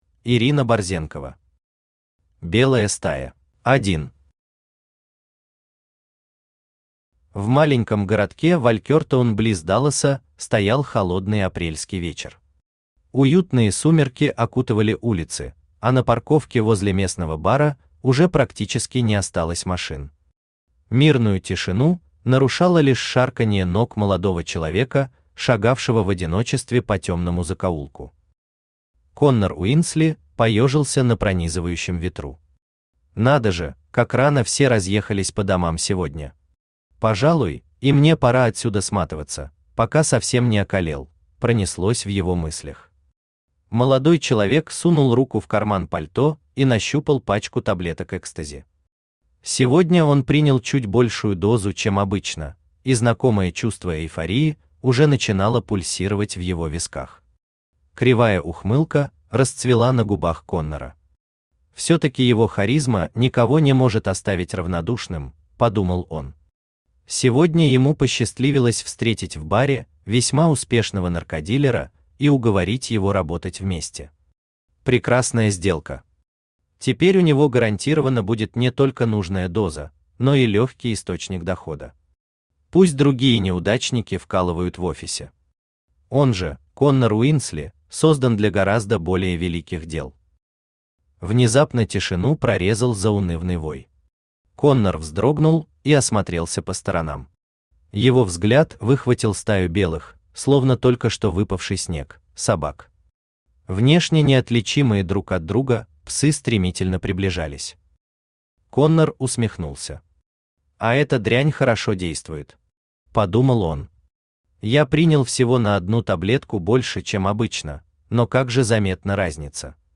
Aудиокнига Белая стая Автор Ирина Борзенкова Читает аудиокнигу Авточтец ЛитРес.